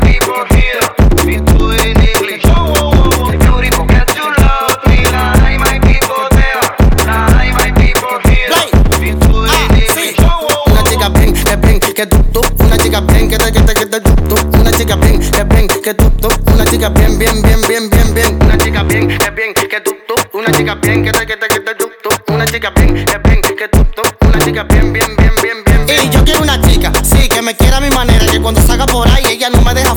Жанр: Латино